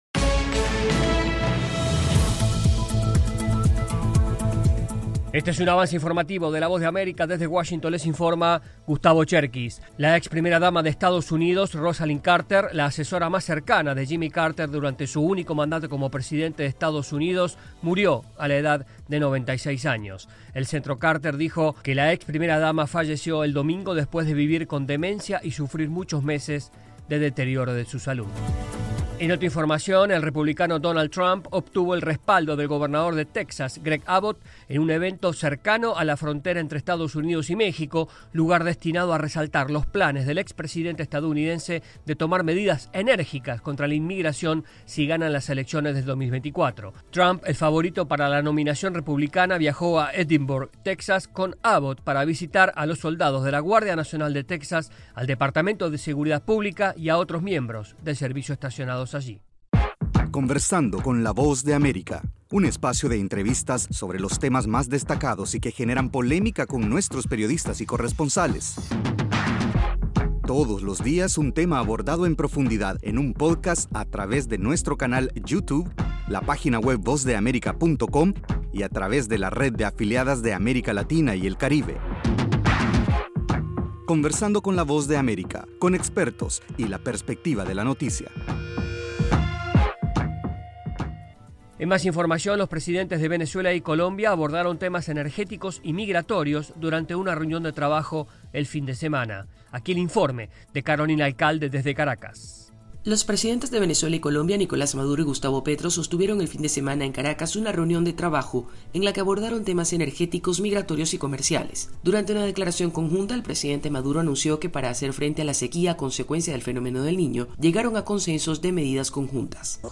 Avance Informativo 6:00AM
Este es un avance informativo de la Voz de América.